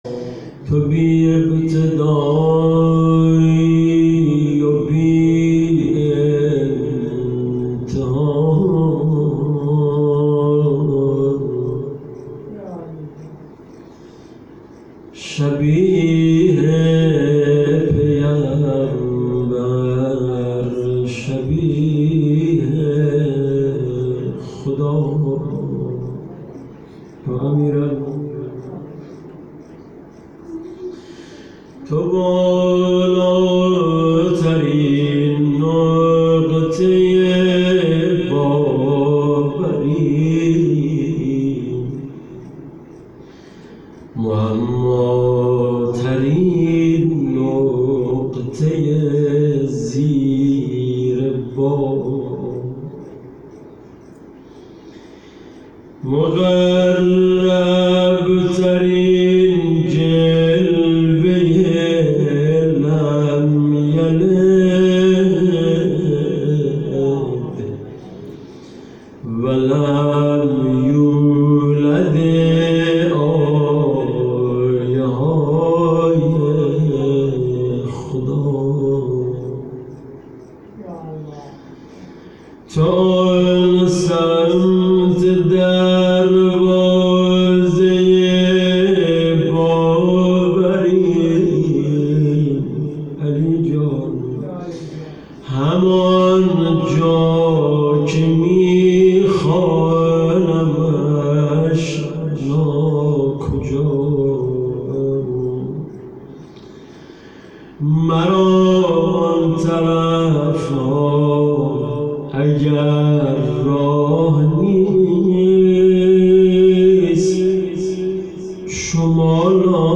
خیمه گاه - هیئت محبان المهدی(عج)آمل - شب 20 ماه رمضان_بخش اول روضه